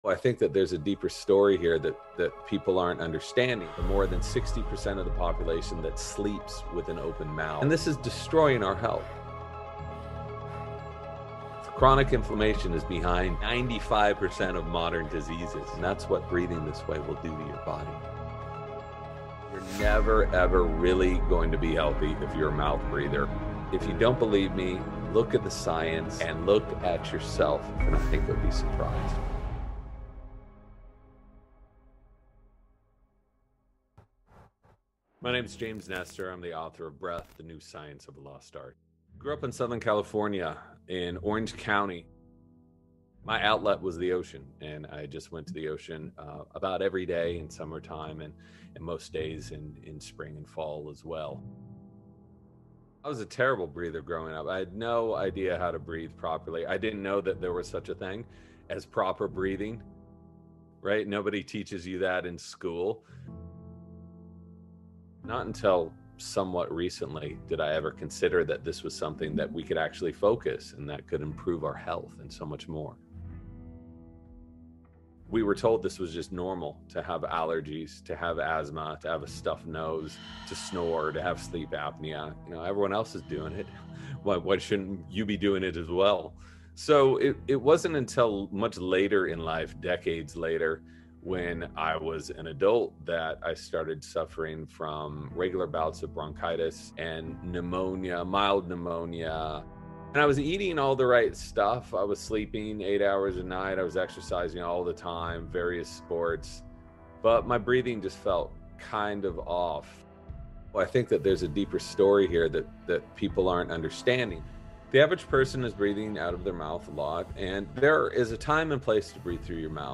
All ads in Quote of Motivation begin right at the start of each episode so nothing interrupts the moment you settle in, breathe, and feel that familiar spark rising.